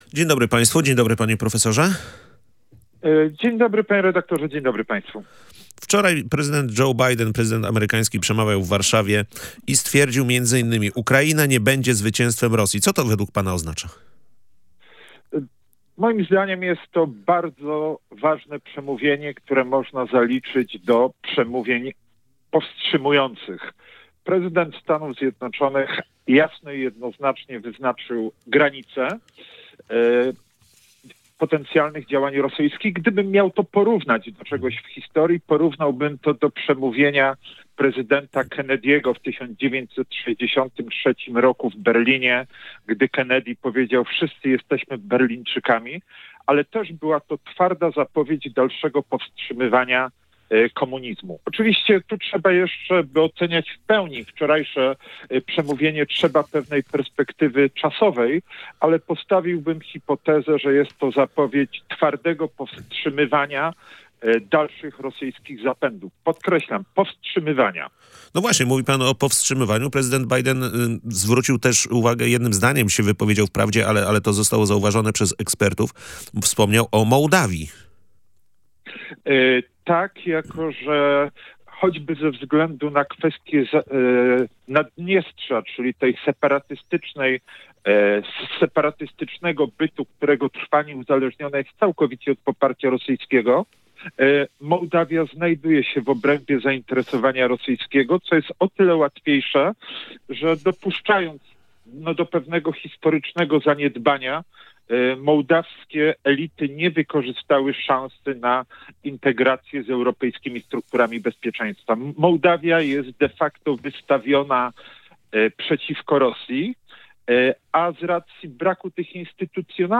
Wizyta prezydenta USA Joe Bidena w Polsce i na Ukrainie była głównym tematem rozmowy